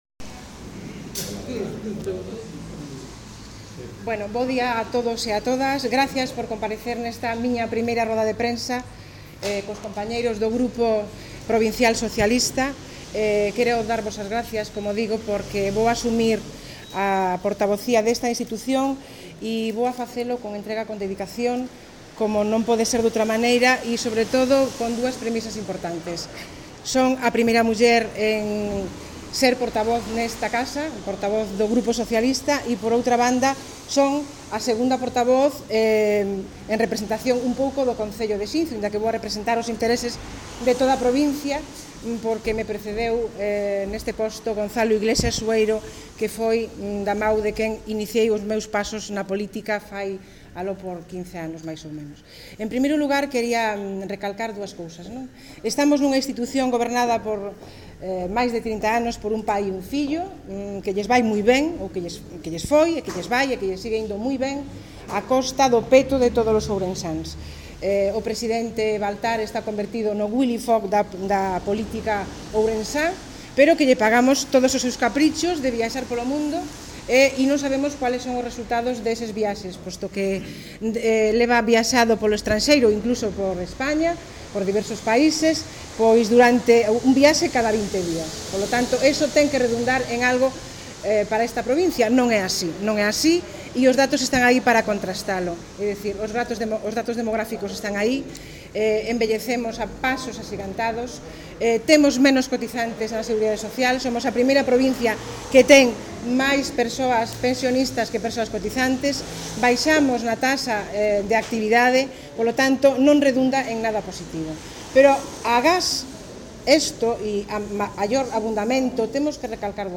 Audio coas declaracións de Elvira Lama